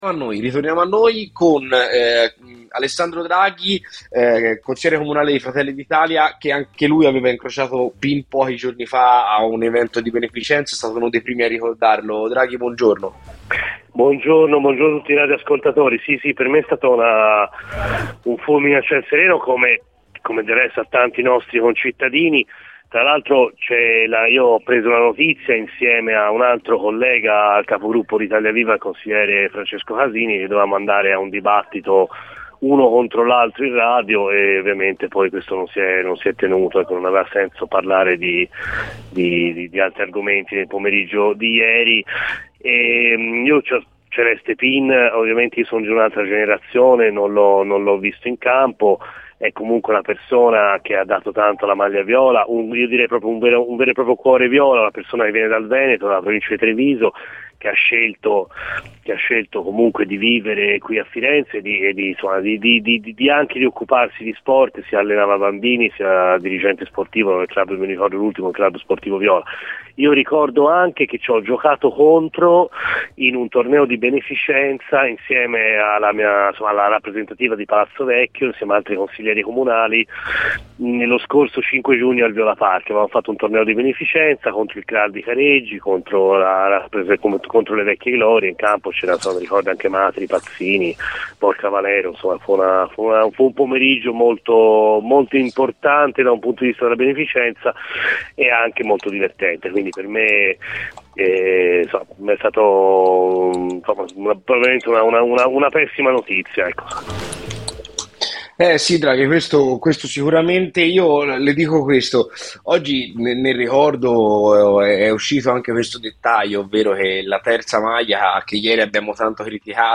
Il Consigliere Comunale di Fratelli d'Italia Alessandro Draghi è intervenuto ai microfoni di Radio FirenzeViola durante la trasmissione "C'è Polemica".